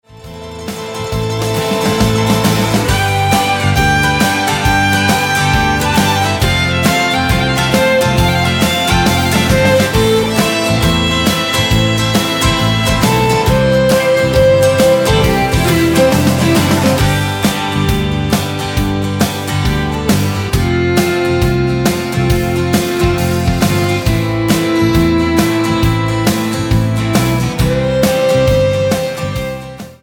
--> MP3 Demo abspielen...
Tonart:G ohne Chor